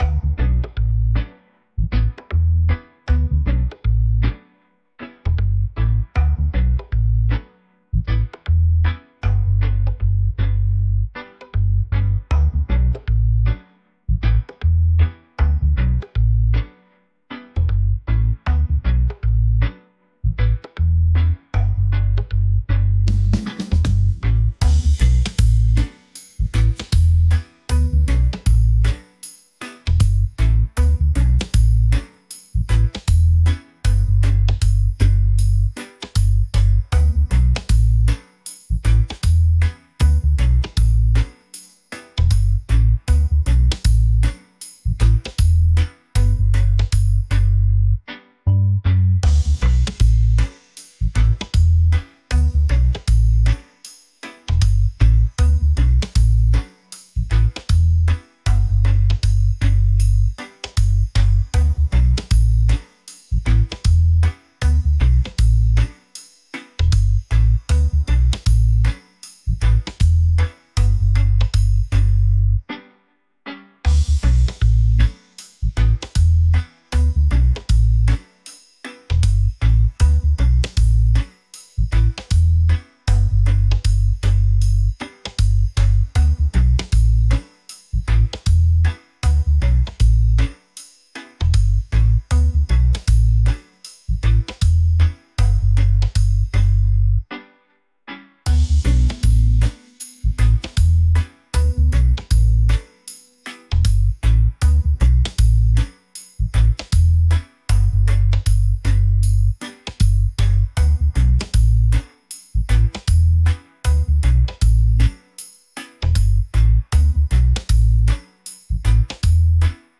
reggae | relaxed